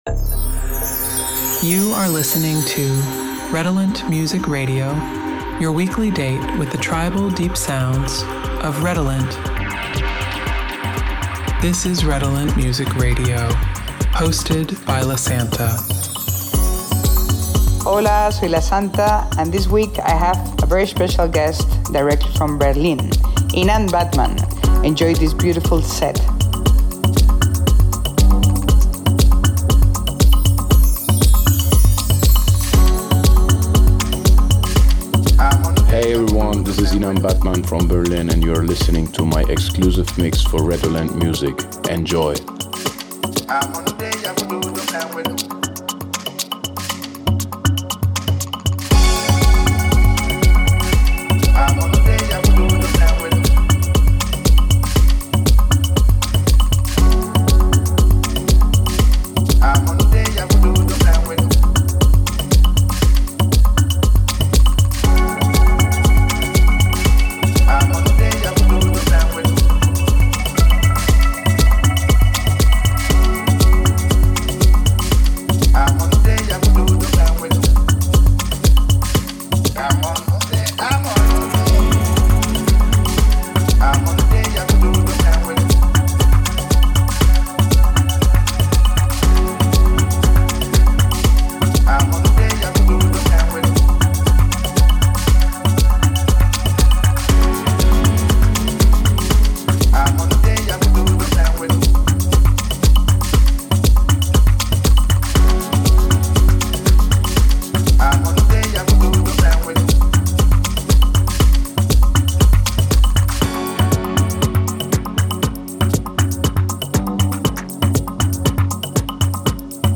Afro Dance Journey